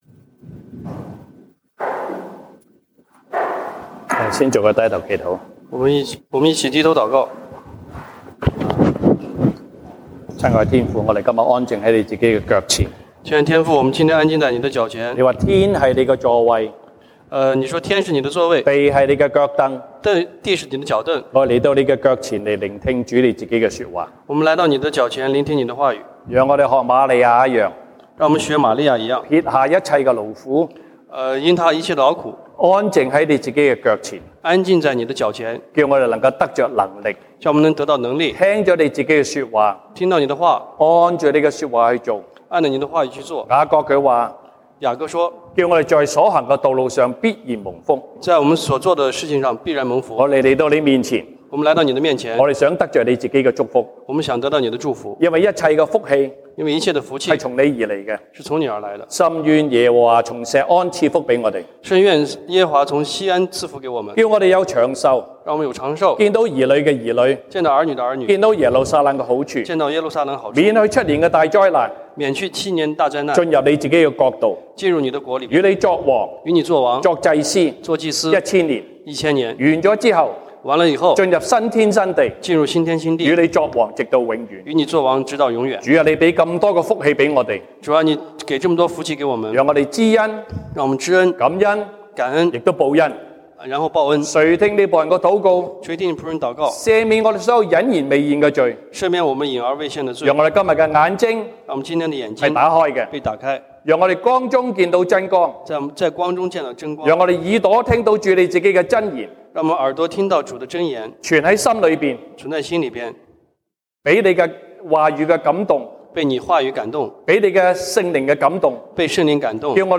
西堂證道(粵語/國語) Sunday Service Chinese: 神喜愛樂捐的人